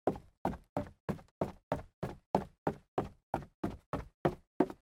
stairs.ogg